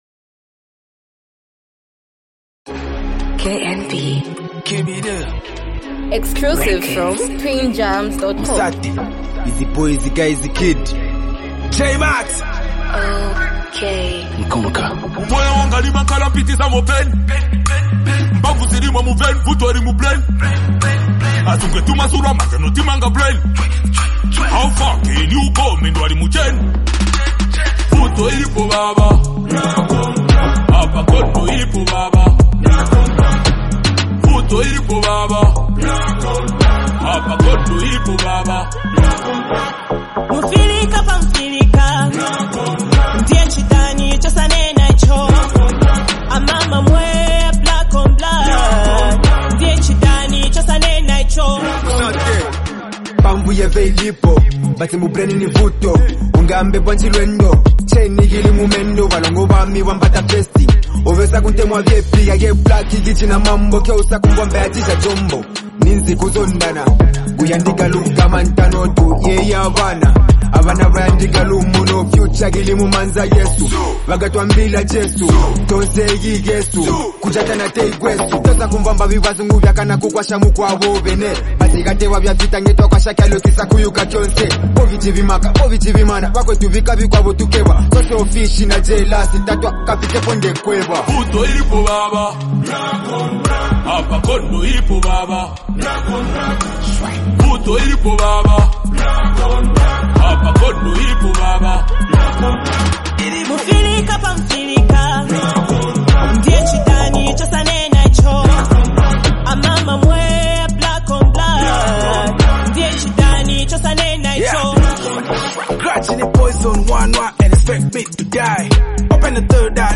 bold and confident track
energetic rap flow
smooth and catchy hook